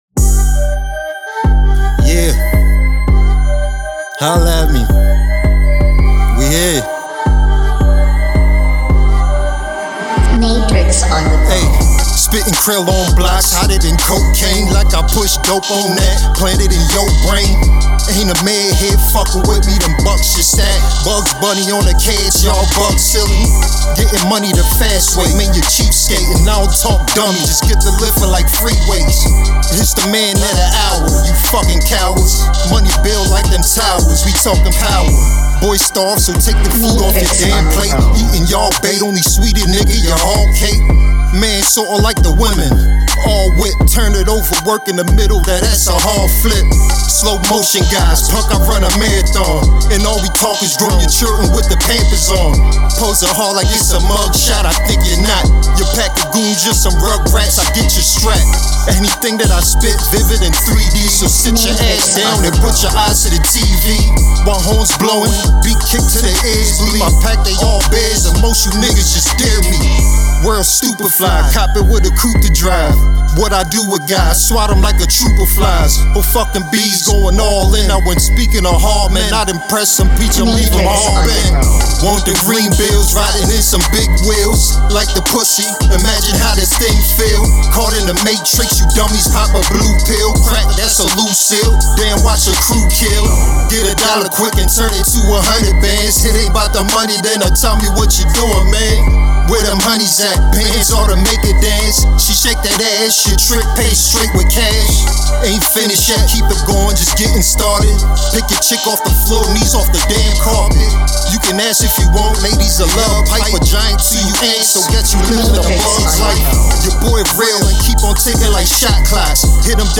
Your Source For Hip Hop News